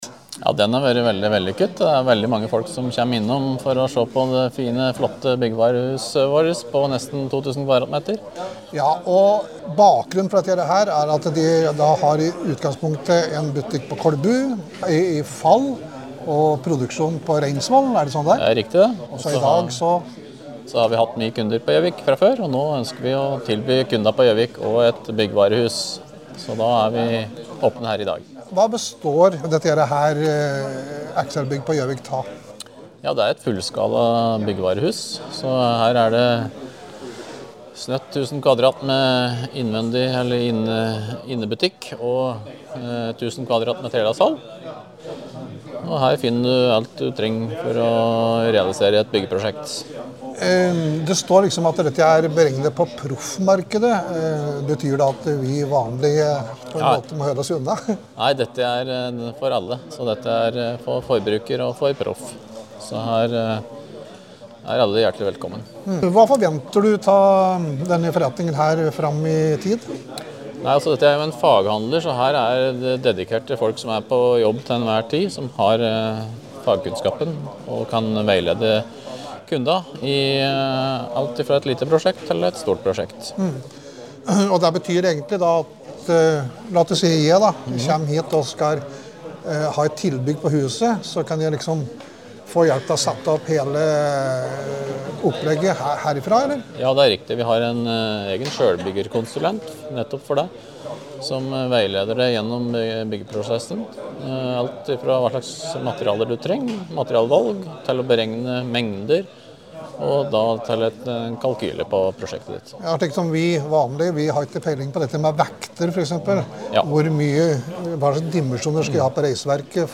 Innslag